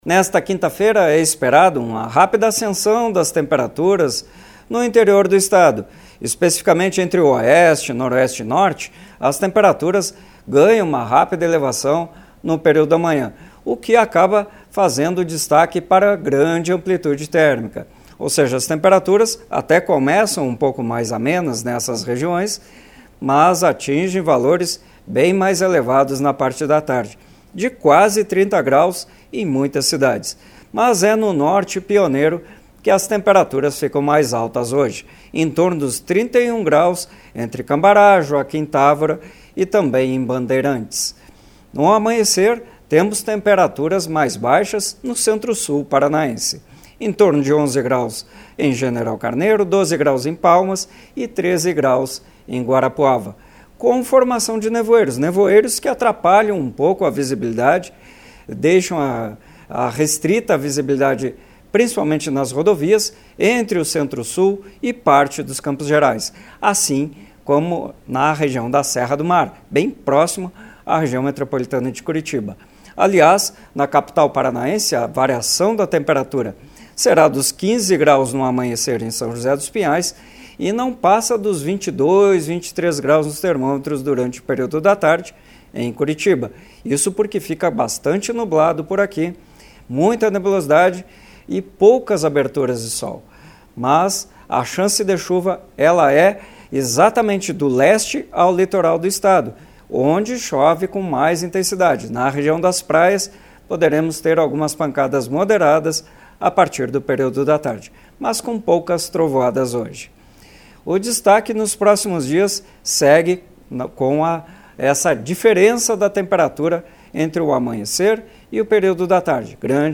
Previsão